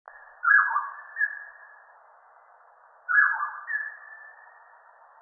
大彎嘴 Pomatorhinus erythrocnemis
嘉義縣 阿里山 阿里山
錄音環境 闊葉林
鳥叫
收音: 廠牌 Sennheiser 型號 ME 67